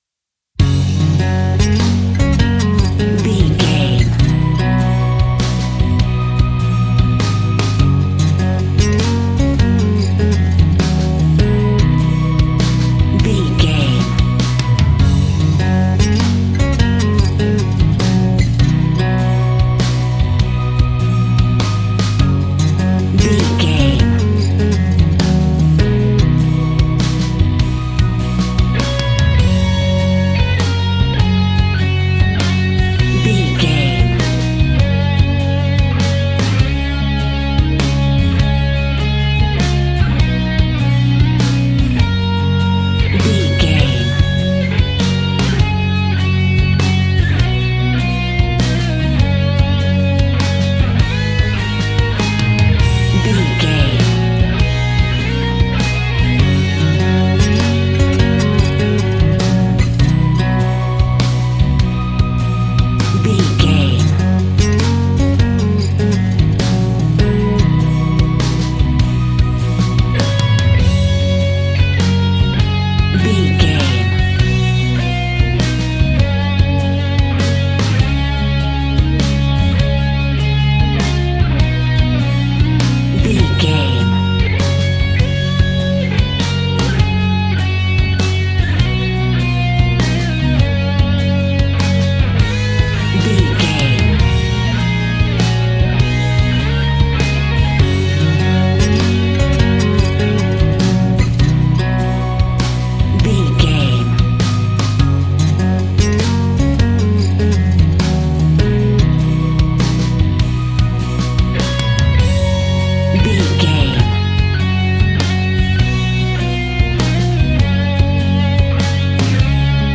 Ionian/Major
indie pop
energetic
uplifting
instrumentals
upbeat
groovy
guitars
bass
drums
piano
organ